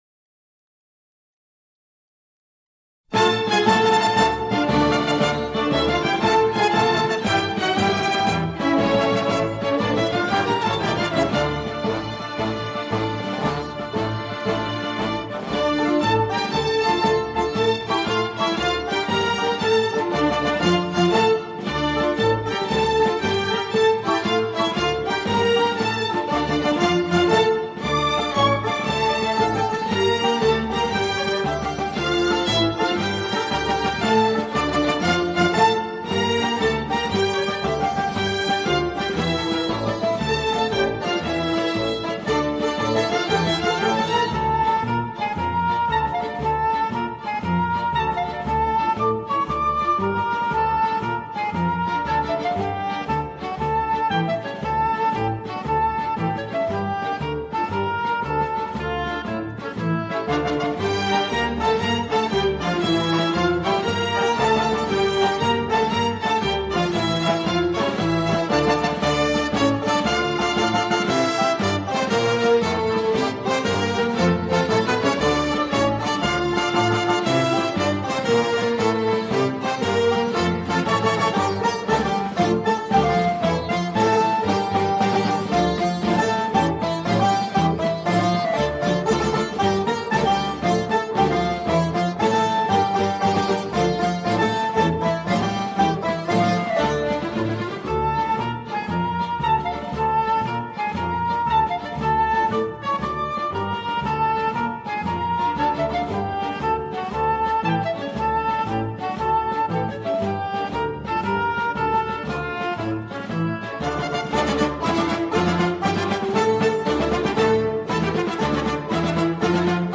سرودهای انقلابی
بی‌کلام